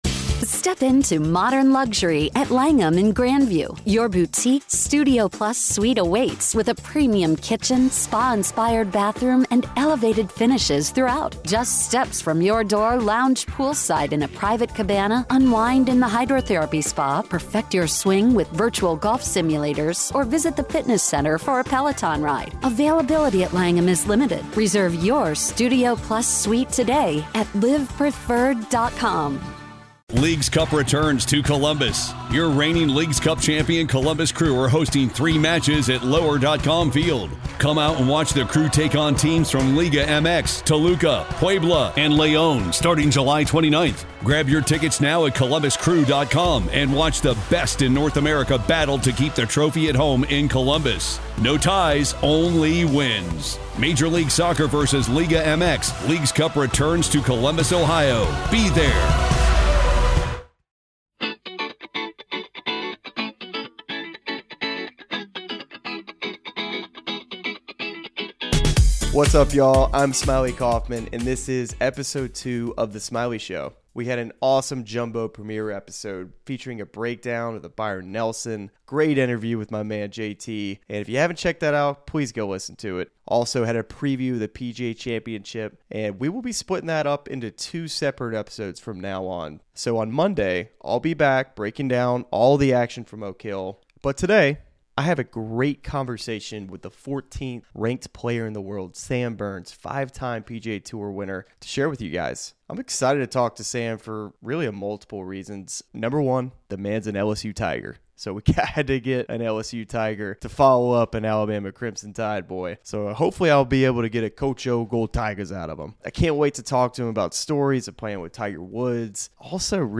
Smylie is joined by a fellow LSU Tiger, Sam Burns, who is currently the 14th-ranked player in the world with five wins on the PGA Tour. Sam tells Smylie about playing with Tiger Woods in his first year on Tour, going head-to-head with his good friend Scottie Scheffler, and goes in-depth on his swing mechanics.